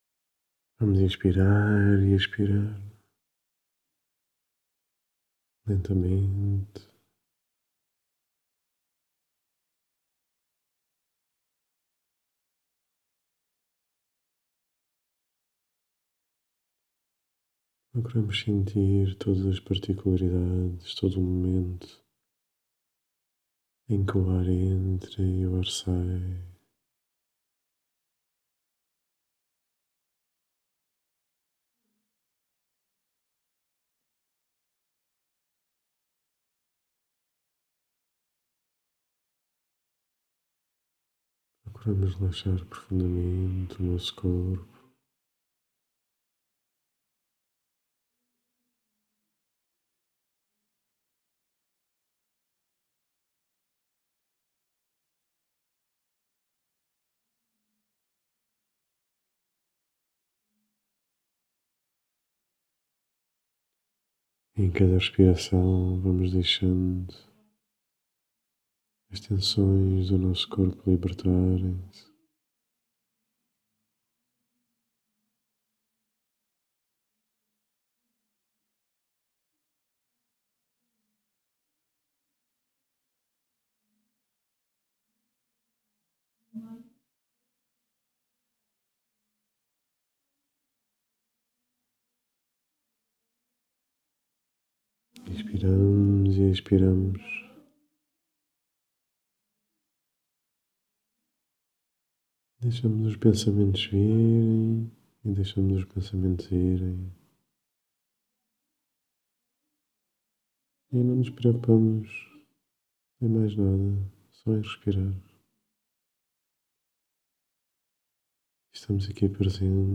Meditação Respirar e Luz - Dia 147